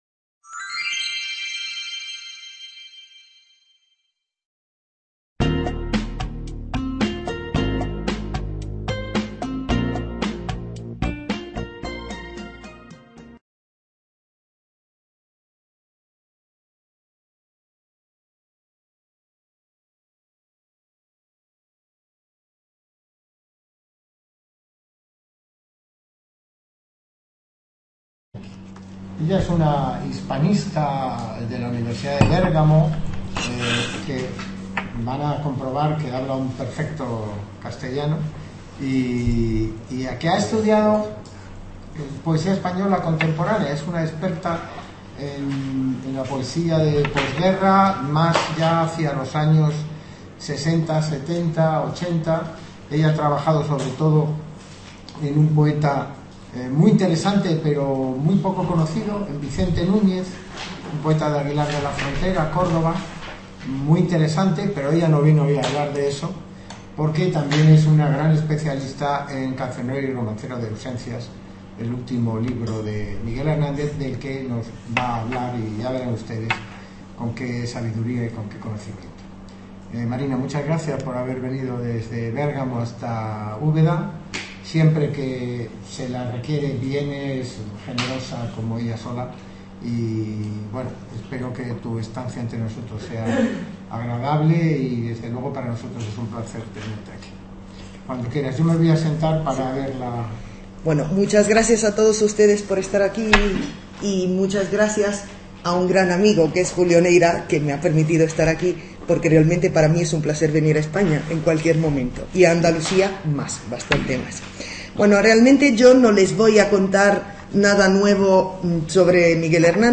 Authorship & License License Rights BY-NC-SA Público Academic Information Room Aula Virtual del Centro Asociado de Jaén Attached Resources Attached Resources Video Movil Audio